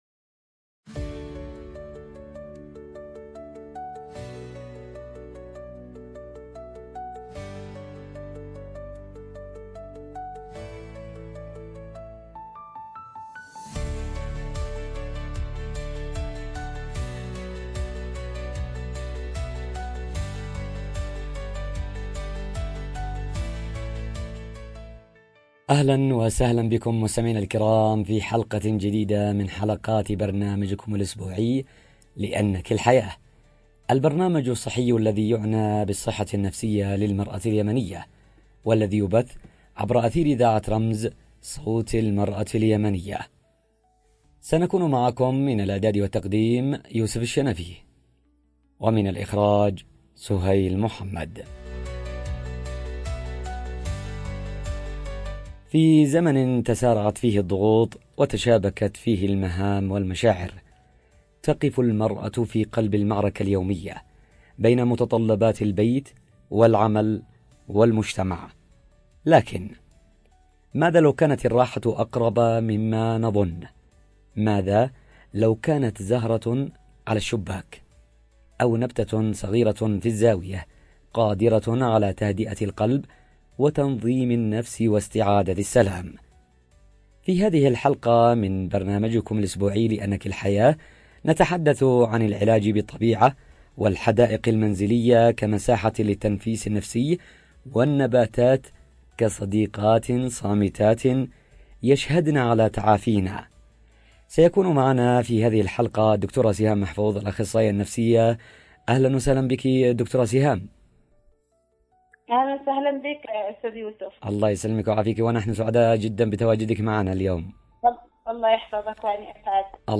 📻 عبر أثير إذاعة رمز